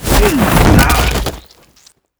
Tackle5.wav